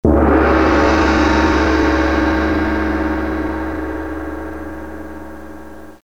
Звуки таймера
Звук завершения таймера гонгом